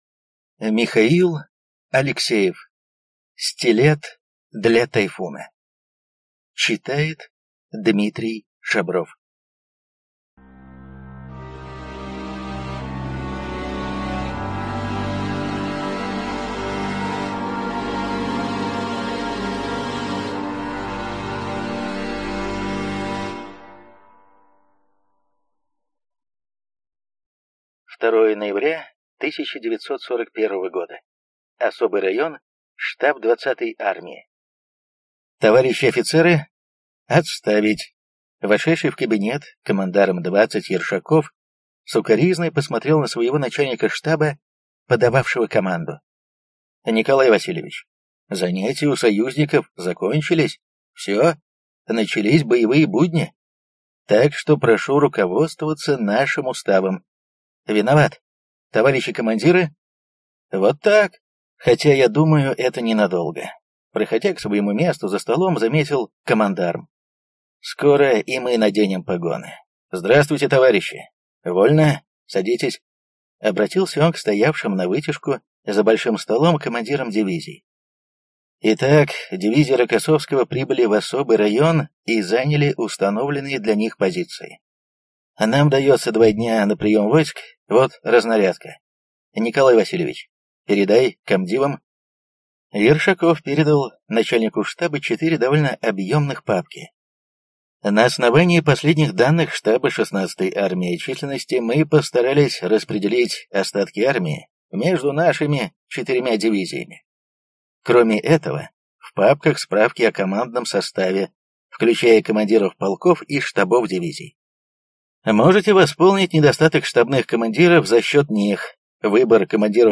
Аудиокнига Стилет для «Тайфуна» | Библиотека аудиокниг